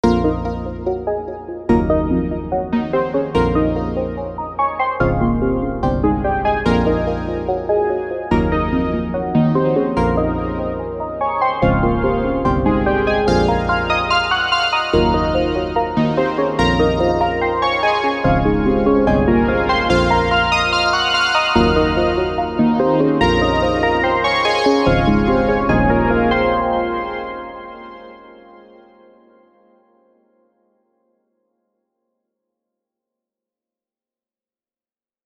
playing with the same instrument set a little more. kind of a mess